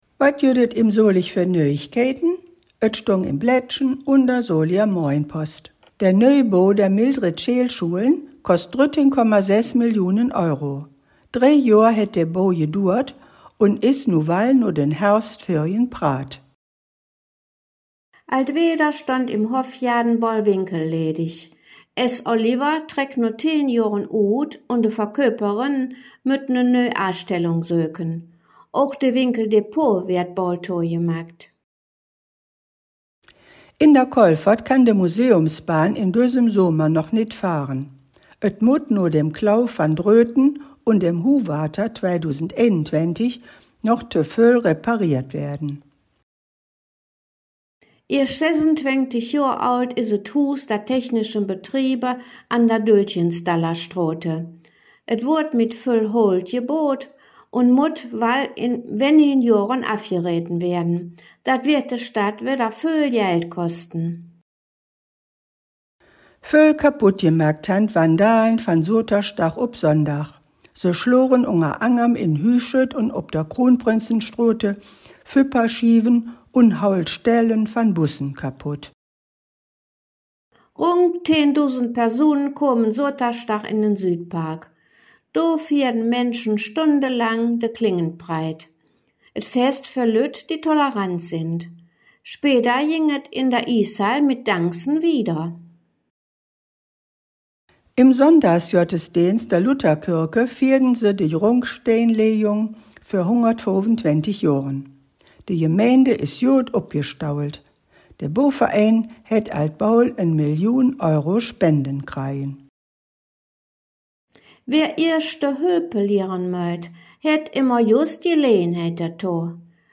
Nöüegkeïten op Soliger Platt